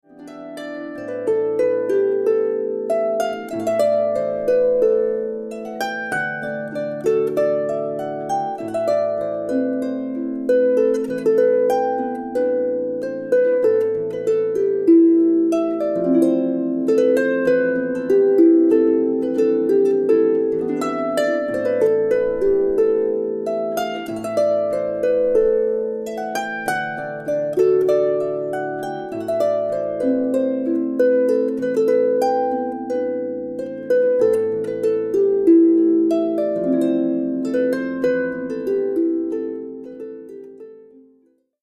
classic Irish songs and melodies